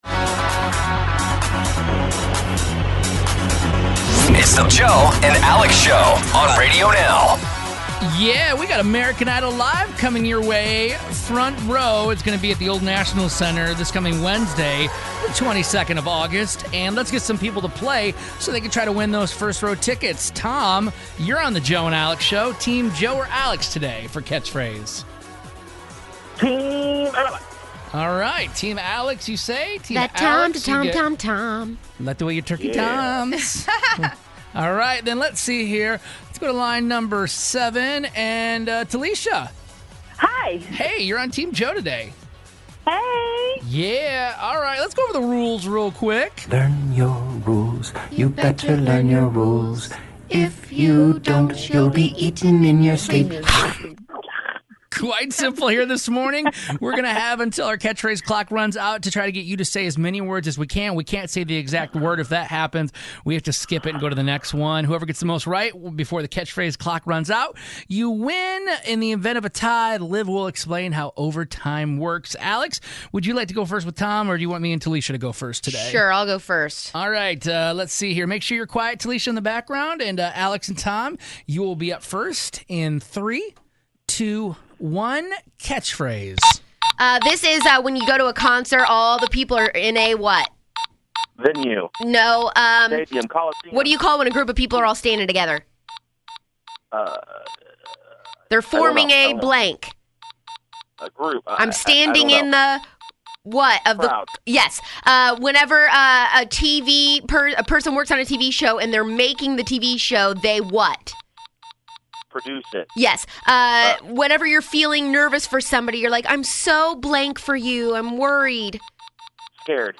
Listeners play Catchphrase for a chance to win American Idol Tour tickets...it was not a good Catchphrase day.